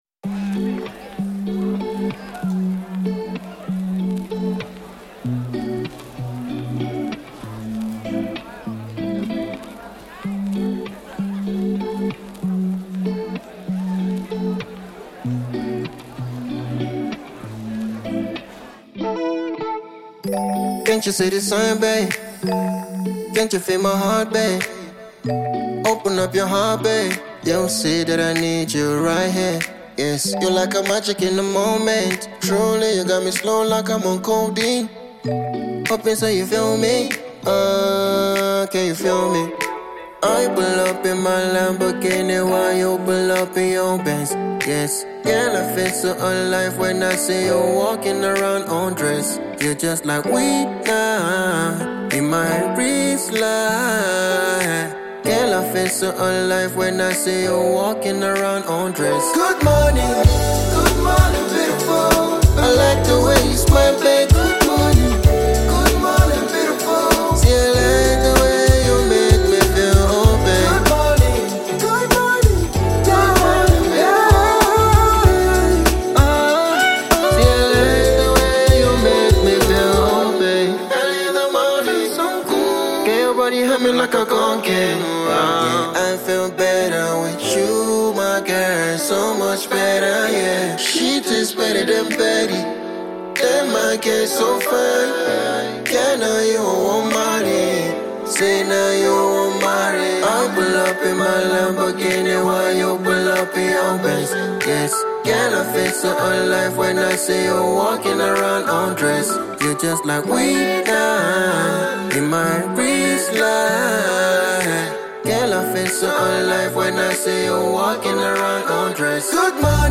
A SUNNY AFROBEAT TRACK TO START THE DAY ON A POSITIVE NOTE